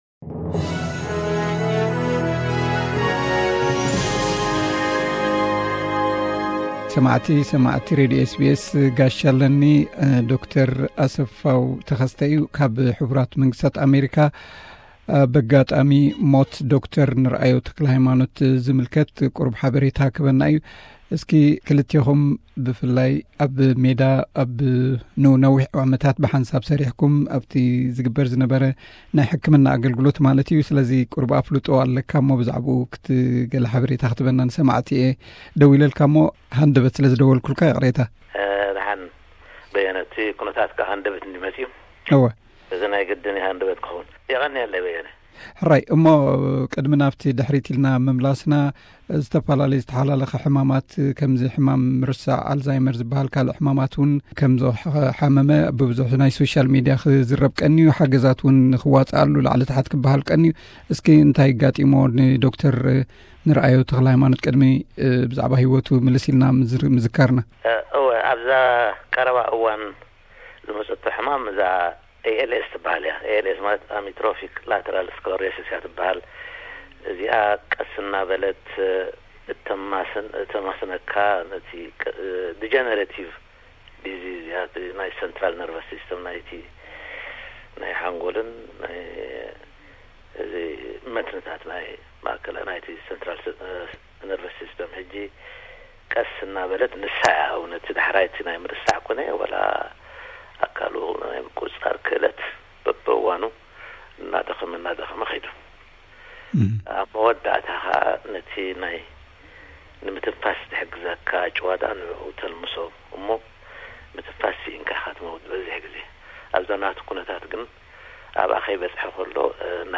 ጻንሒት